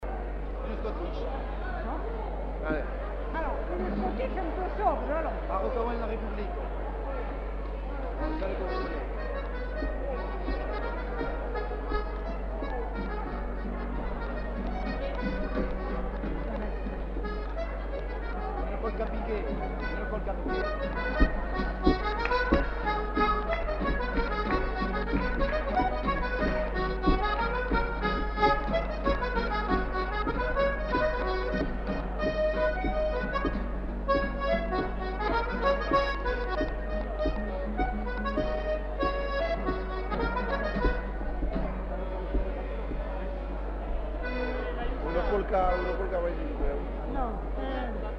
Lieu : Samatan
Genre : morceau instrumental
Instrument de musique : accordéon diatonique ; guitare
Danse : scottish
Notes consultables : Le joueur de guitare n'est pas identifié.